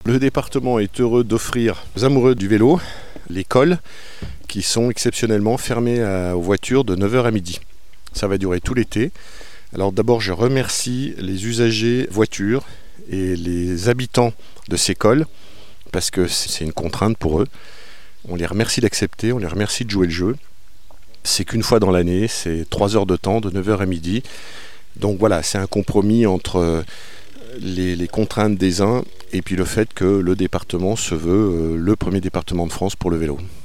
Martial Saddier, président du conseil départemental de la Haute-Savoie, revient sur l’organisation de ces rendez-vous hebdomadaires estivaux :